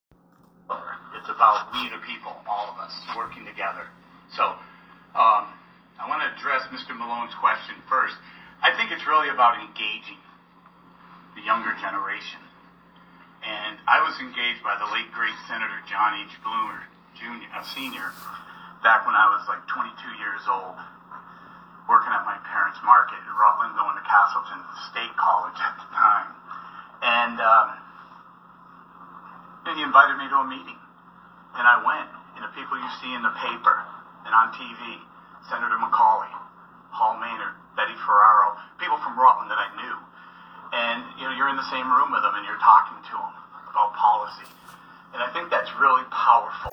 Today, we offer their quotes and clips, recorded at the same breakfast.